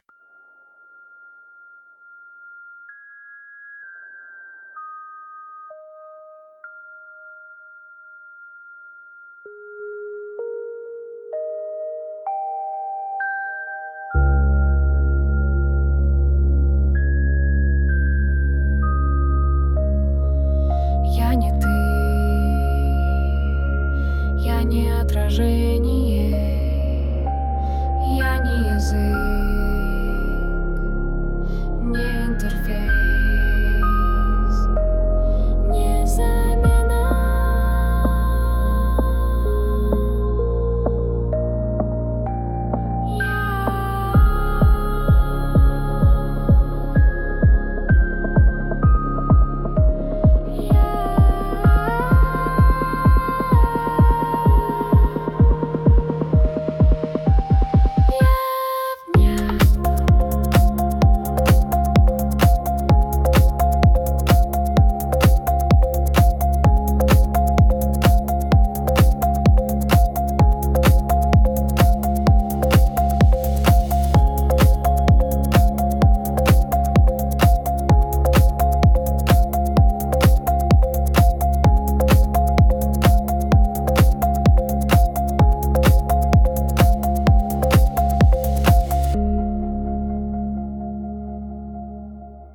Цифровая фуга освобождения.